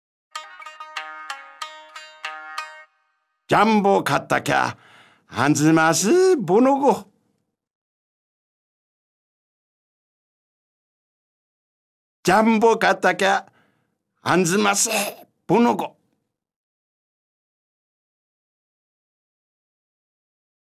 本場のお国言葉を読み上げる方言かるたシリーズ第二弾「津軽弁かるた」が新登場！